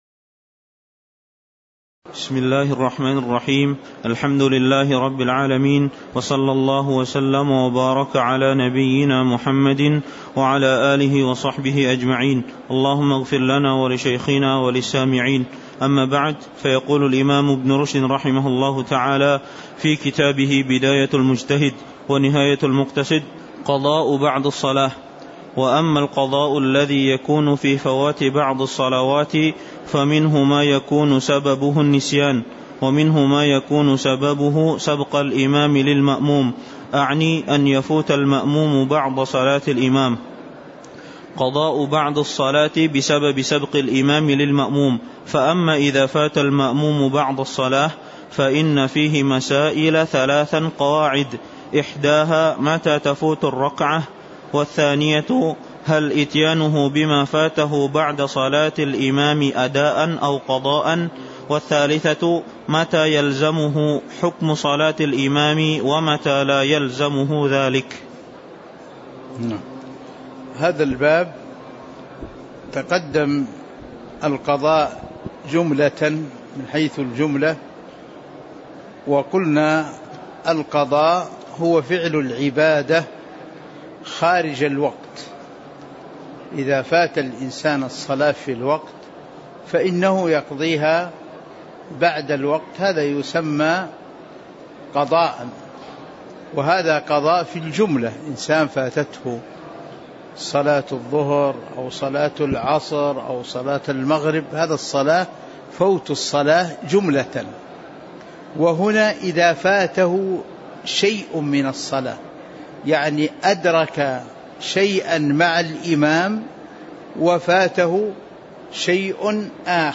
تاريخ النشر ٢٠ ربيع الأول ١٤٤٤ هـ المكان: المسجد النبوي الشيخ